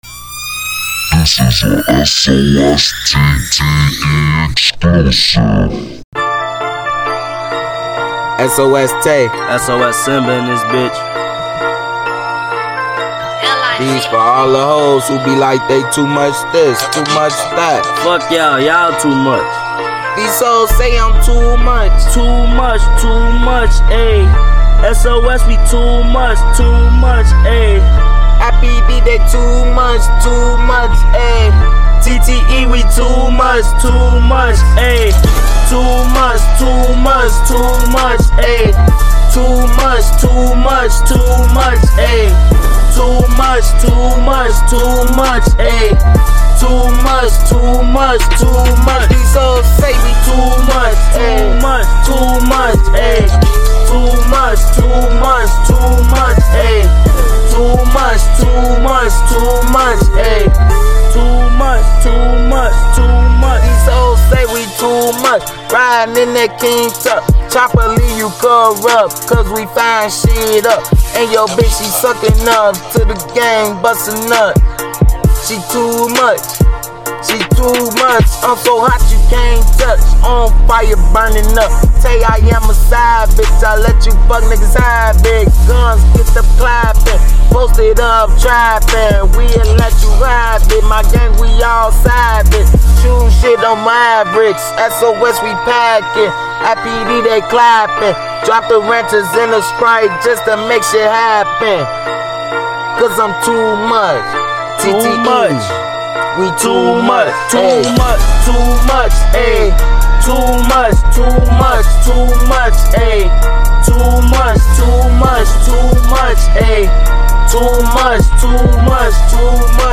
has some of the hardest lines and metaphors